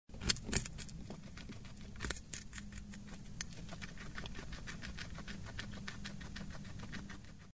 Звуки морских свинок